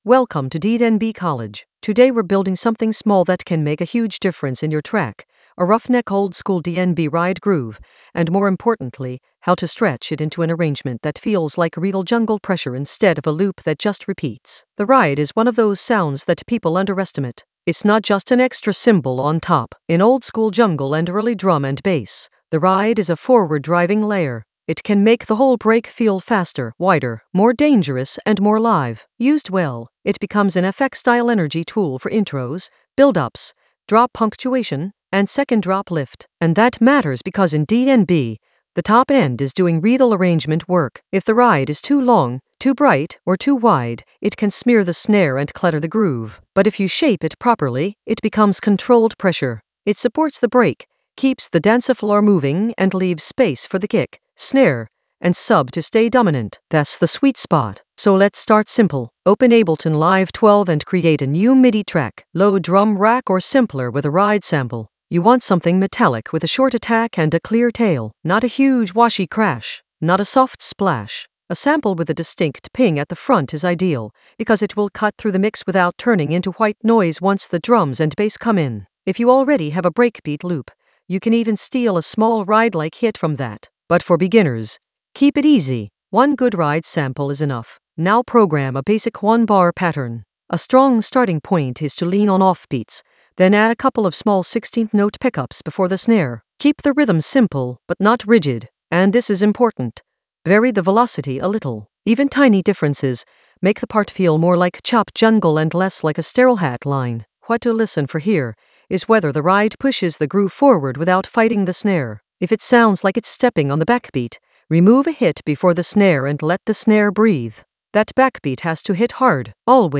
An AI-generated beginner Ableton lesson focused on Ruffneck an oldskool DnB ride groove: stretch and arrange in Ableton Live 12 for jungle oldskool DnB vibes in the FX area of drum and bass production.
Narrated lesson audio
The voice track includes the tutorial plus extra teacher commentary.